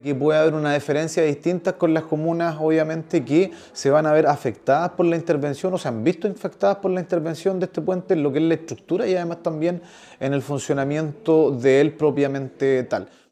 Por su parte, el alcalde de Hualpén, Miguel Rivera, pidió una deferencia para los habitantes de la comuna que se vieron afectados por la intervención durante la construcción y en el funcionamiento del puente.
alcalde-hualpen.mp3